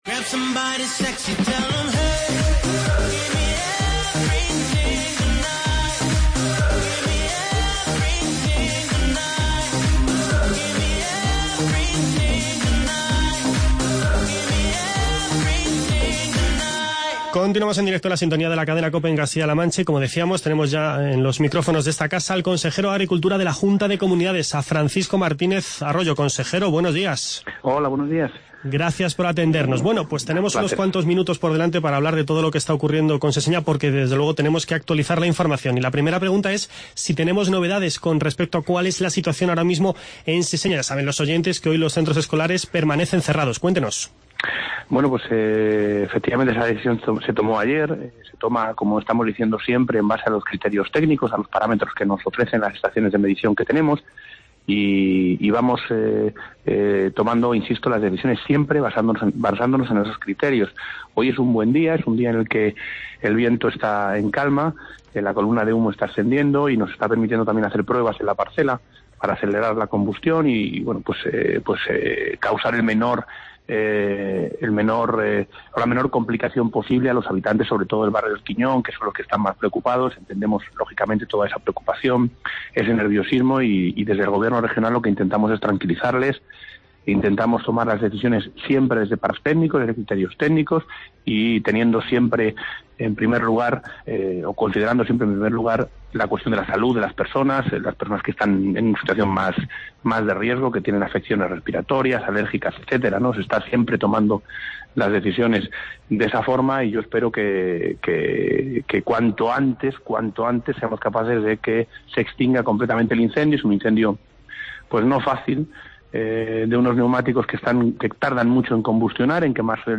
Charlamos con el consejero de Agricultura de la Junta de Comunidades, Francisco Martínez Arroyo.